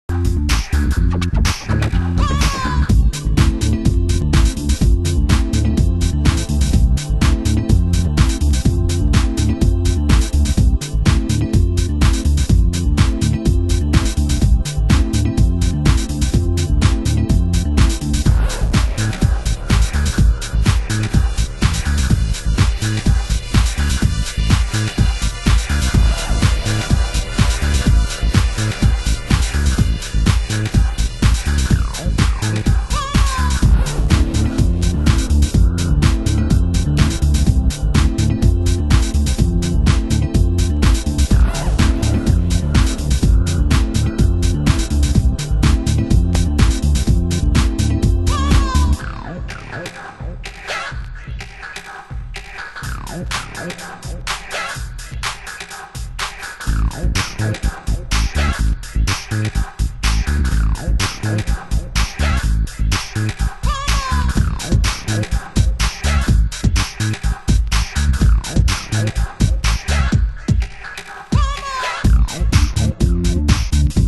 大胆な展開で飽きさせない、アシッド・ブレイクス！